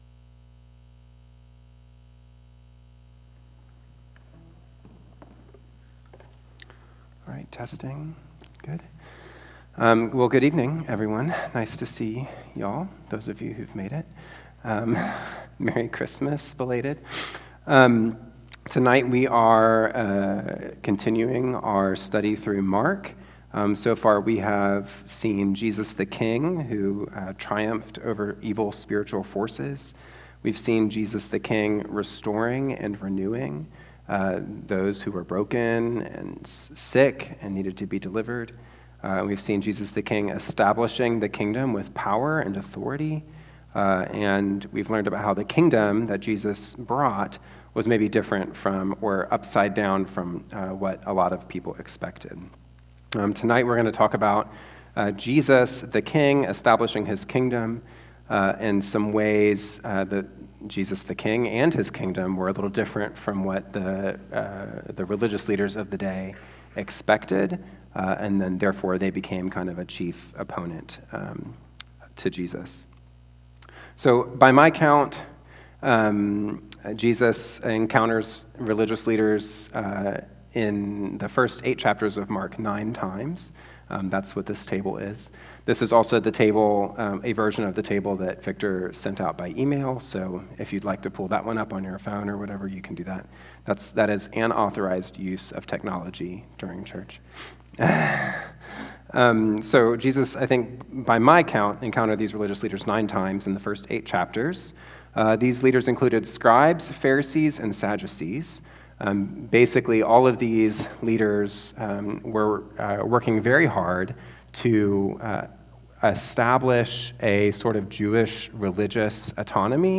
Weekly Sermons from Lexington Christian Fellowship